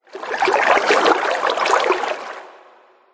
Minecraft Water Addition